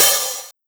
80s Digital Open Hat 01.wav